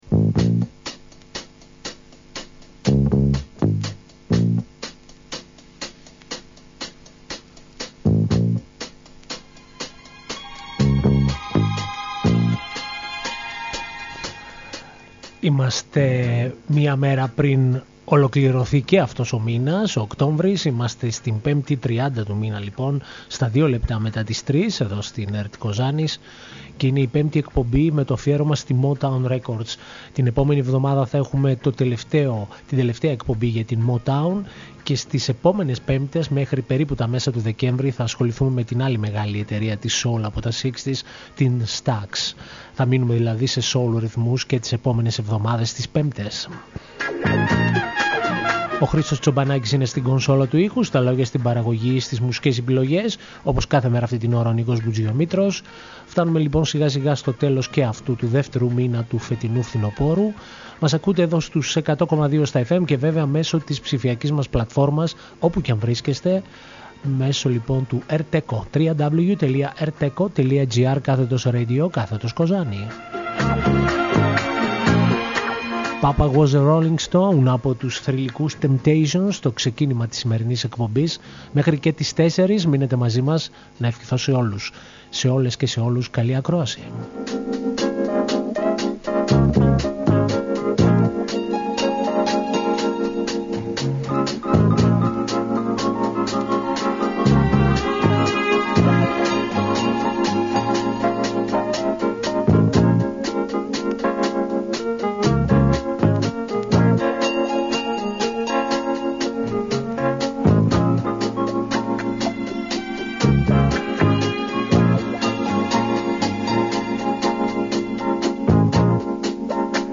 soul μουσική